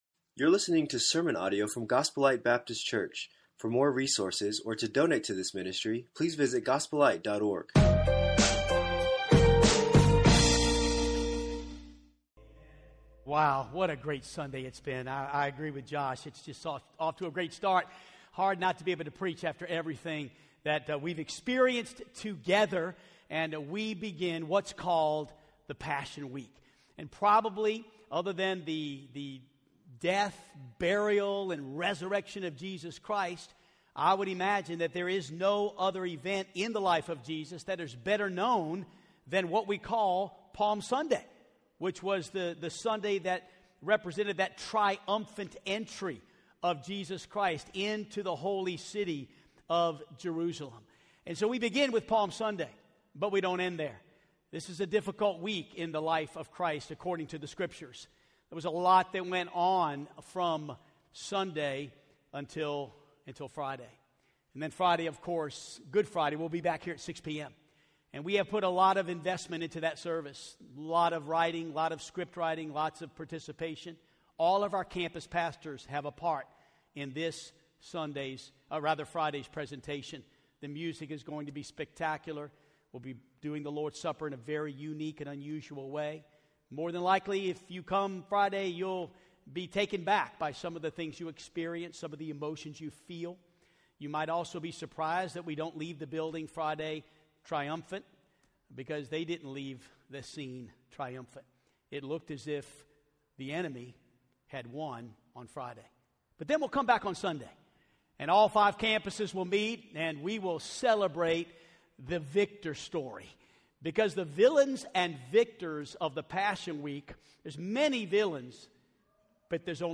Villains and Victors - Sermon 2